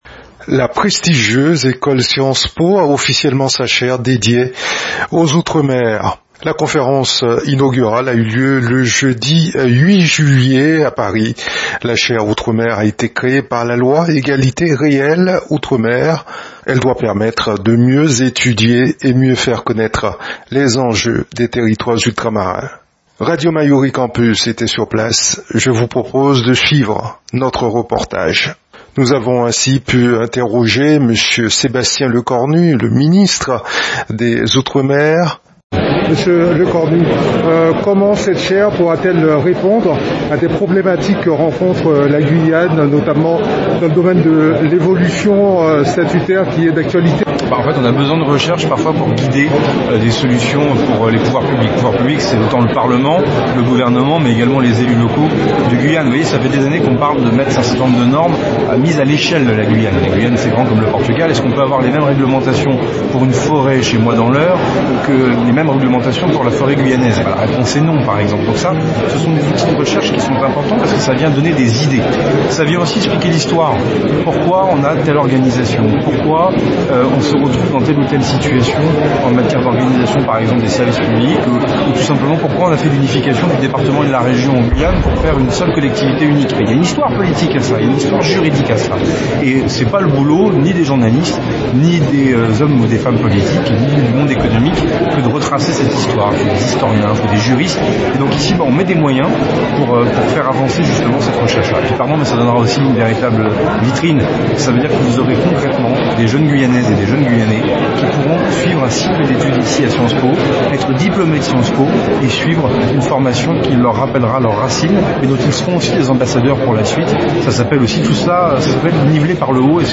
Radio Mayouri Campus avait fait le déplacement, suivez notre reportage